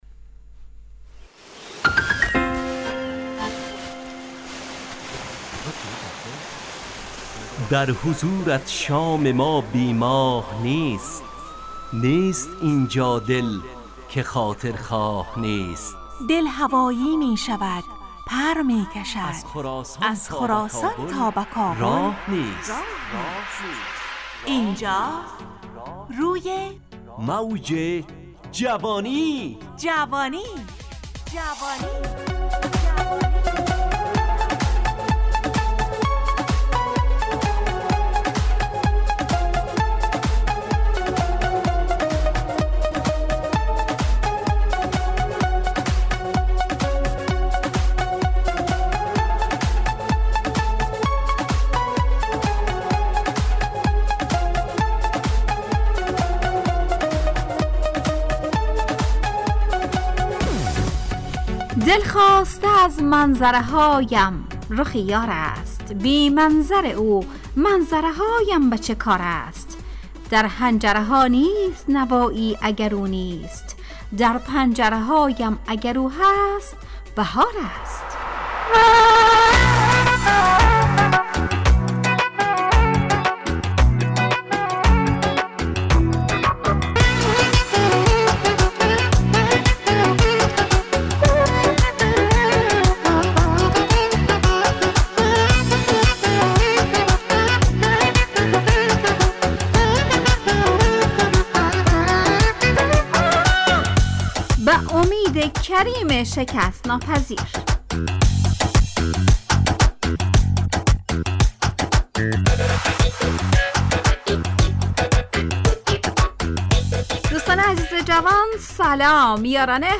همراه با ترانه و موسیقی مدت برنامه 70 دقیقه . بحث محوری این هفته (شکست) تهیه کننده